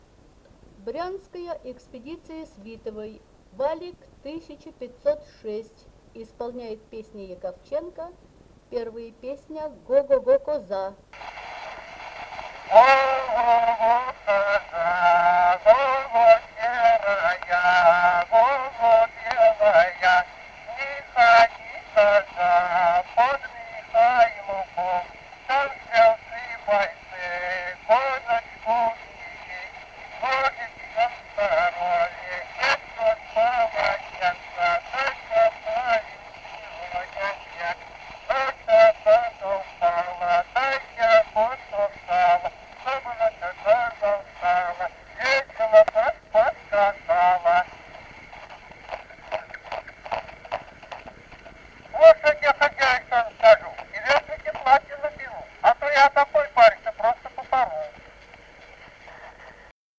Народные песни Стародубского района «Го-го-го, коза», новогодняя щедровная.
д. Камень.